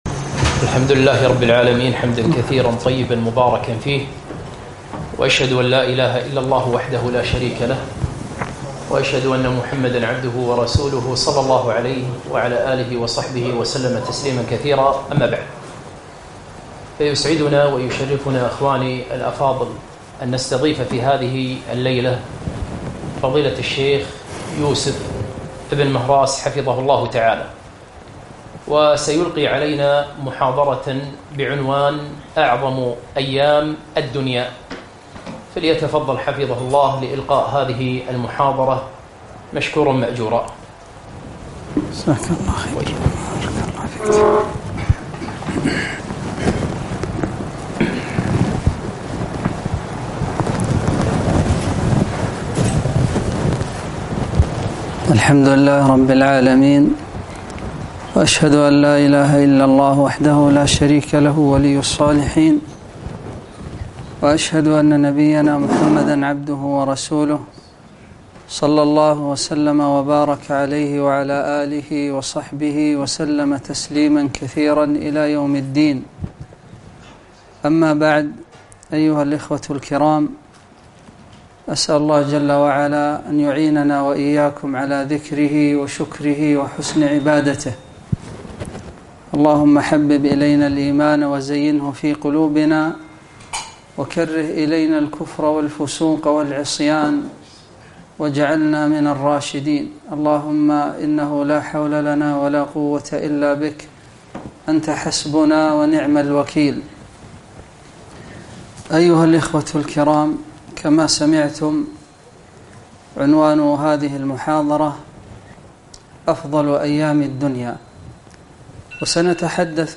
محاضرة - أفضل أيام الدنيا